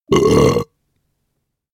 جلوه های صوتی
دانلود صدای آروغ از ساعد نیوز با لینک مستقیم و کیفیت بالا
برچسب: دانلود آهنگ های افکت صوتی انسان و موجودات زنده